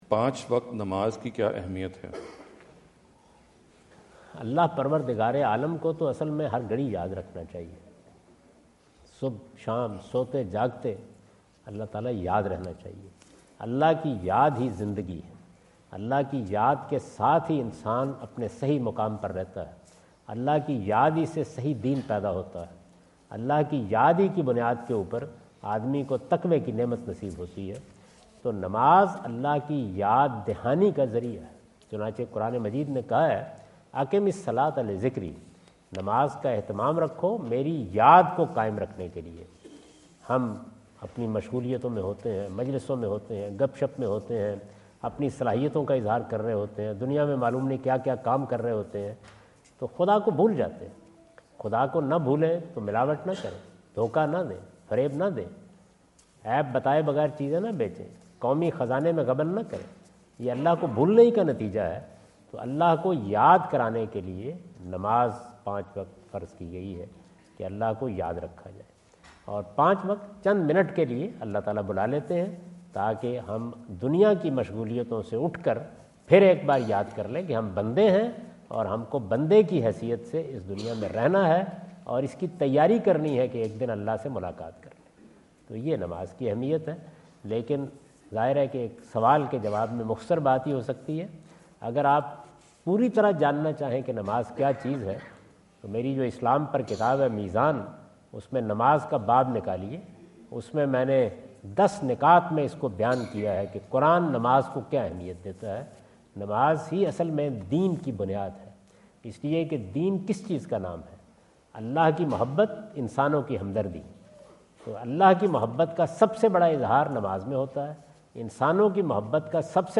Category: English Subtitled / Questions_Answers /
Javed Ahmad Ghamidi answer the question about "Importance of Daily Prayers" asked at The University of Houston, Houston Texas on November 05,2017.
جاوید احمد غامدی اپنے دورہ امریکہ 2017 کے دوران ہیوسٹن ٹیکساس میں "نماز پنجگانہ کی اہمیت" سے متعلق ایک سوال کا جواب دے رہے ہیں۔